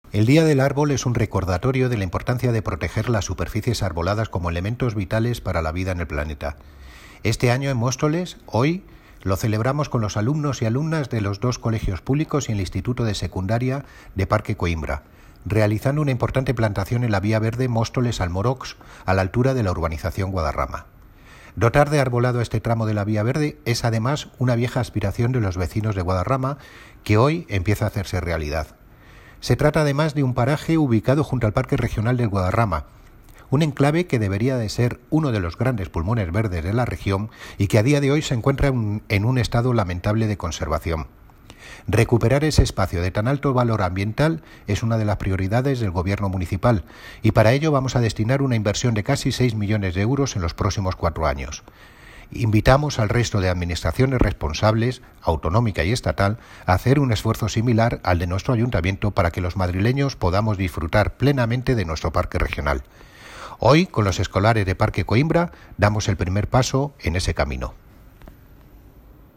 Audio - Miguel Ángel Ortega (Concejal de Medio Ambiente, Parques y Jardines y Limpieza Viaria) Sobre Dia Arbol